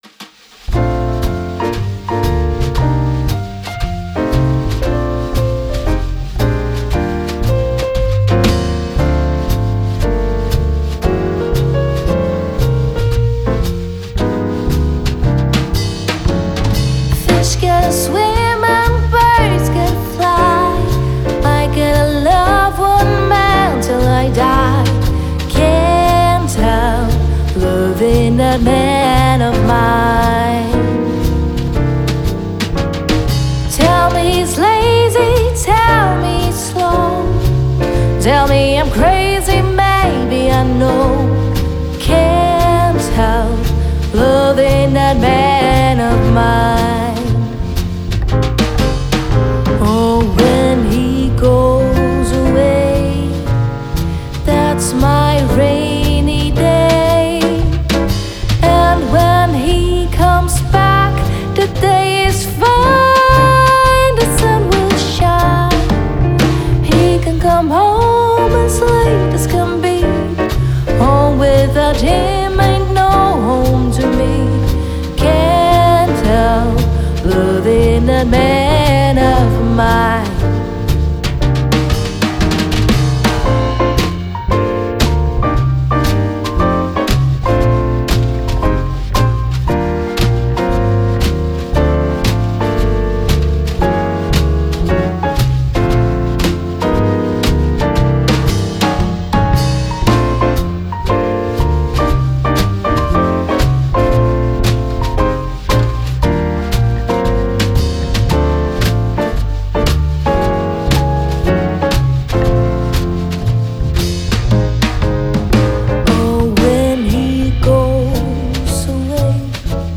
Gesang, Bandleaderin
Klavier
E-Bass, Kontrabass
Schlagzeug